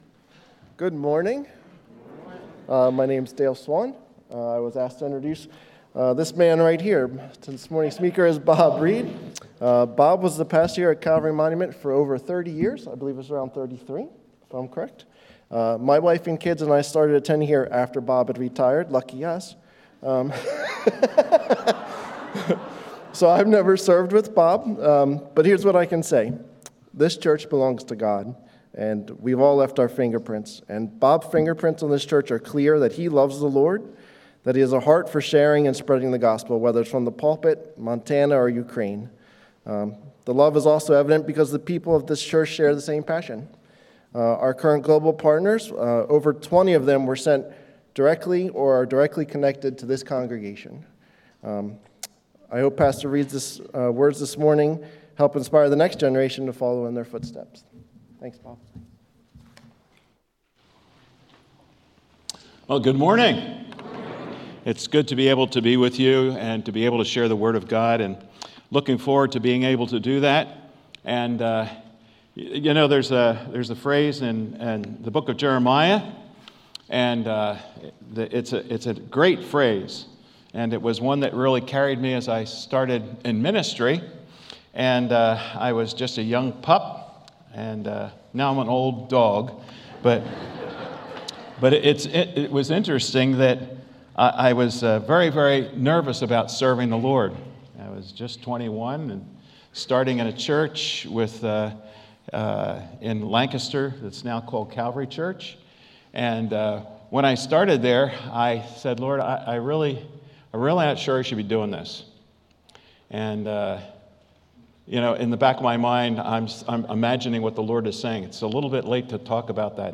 Sermons | Calvary Monument Bible Church
Global Outreach Conference 2026